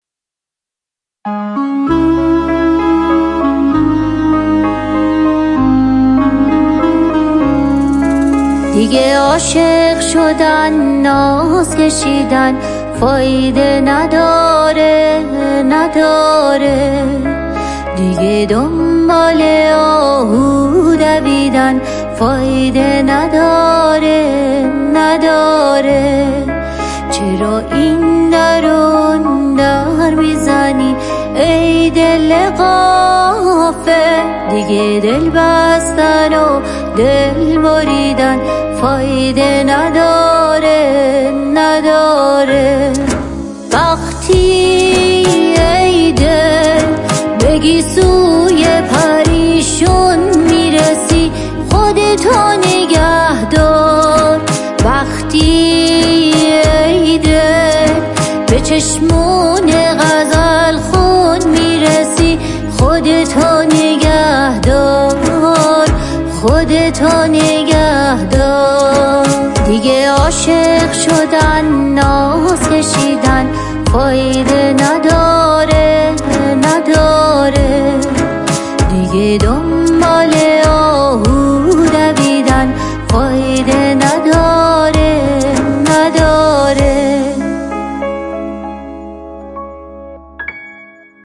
پاپ
بازخوانی